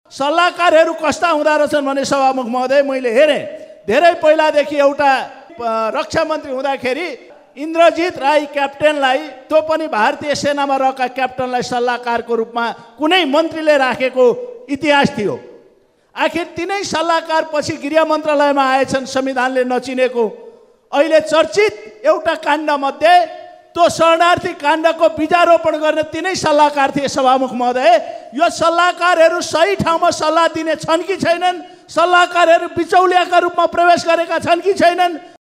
बिहीबारको प्रतिनिधि सभा बैठकको बिशेष समयमा उनले प्रदेशका सांसददेखि र राष्ट्रपतिसम्मले राख्ने सल्लाहकारको संख्या ४६० जना रहेको भन्दै सल्लाहकारहरू नै विचौलियाका रूपमा प्रवेश हुन् कि भन्दै प्रश्न गरेका छन् ।